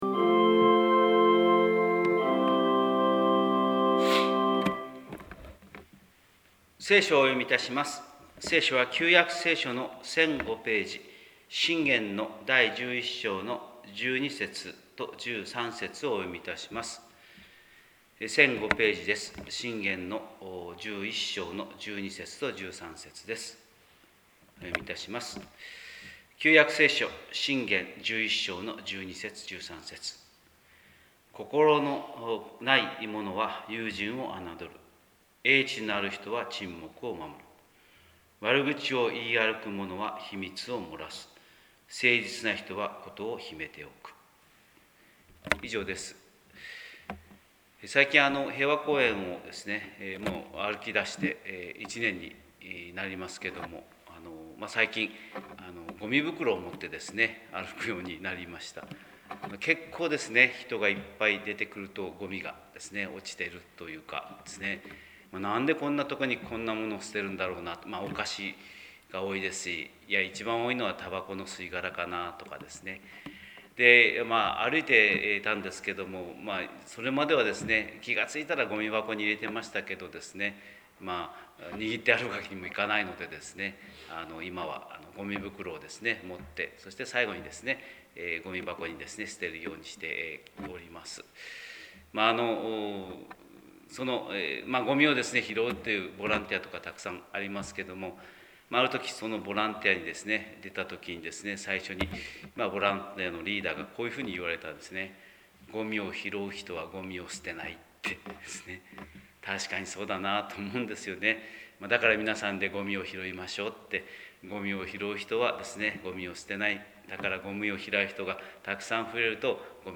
神様の色鉛筆（音声説教）
広島教会朝礼拝210331